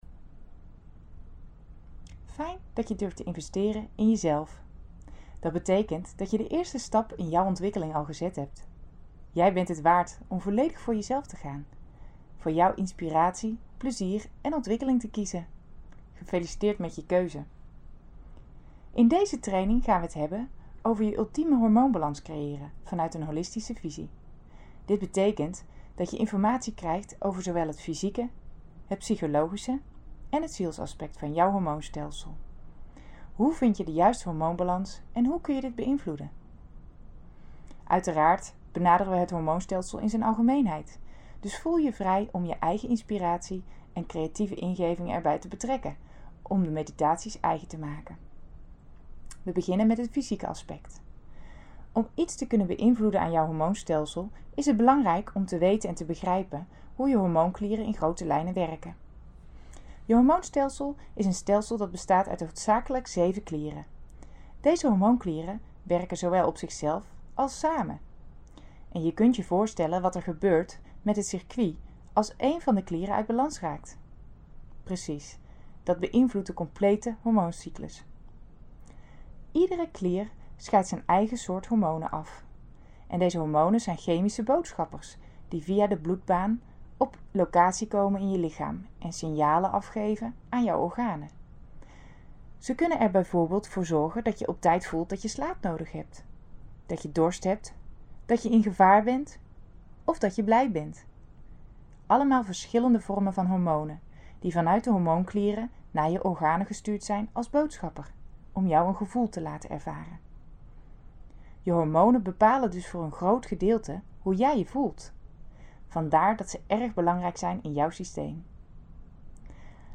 Meditatie-Hormoonbalans-deel-1.mp3